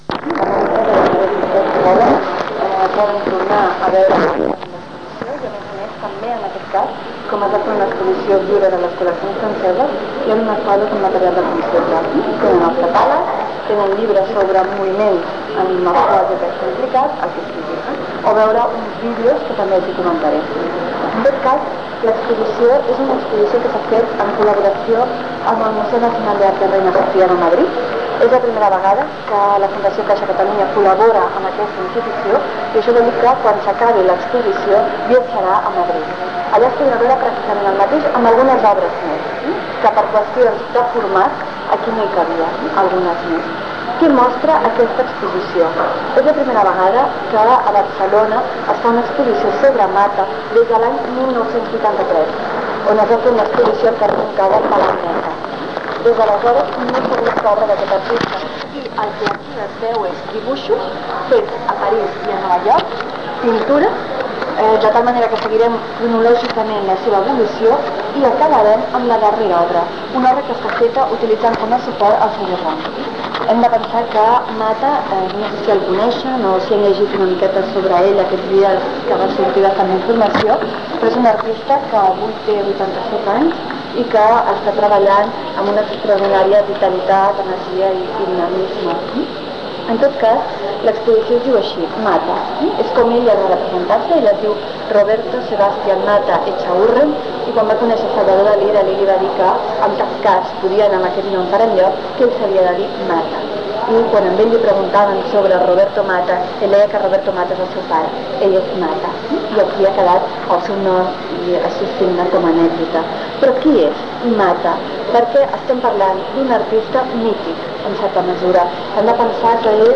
Corpus Oral de Registres (COR). CULT2. Visita comentada
El COR és un component del Corpus de Català Contemporani de la Universitat de Barcelona (CCCUB), un arxiu de corpus de llengua catalana oral contemporània que ha estat confegit pel grup de recerca Grup d'Estudi de la Variació (GEV) amb la finalitat de contribuir a l'estudi de la variació dialectal, social i funcional en la llengua catalana.